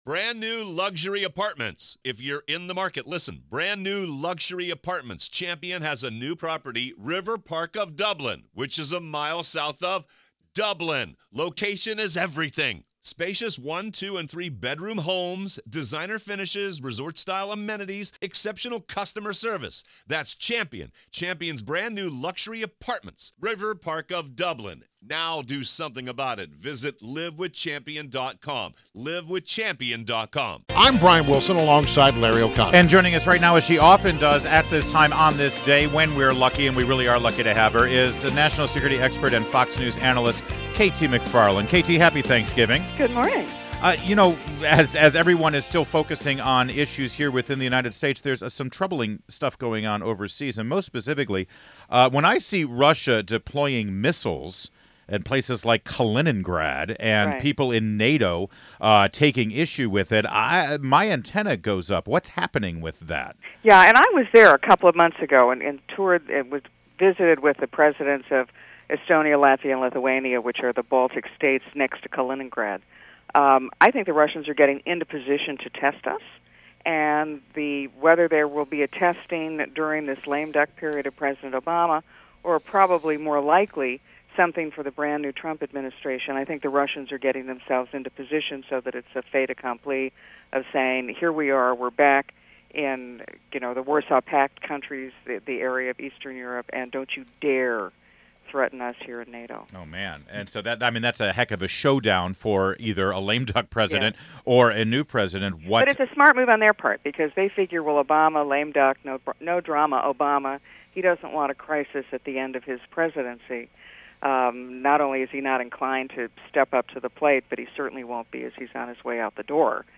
WMAL Interview - KT MCFARLAND - 11.23.16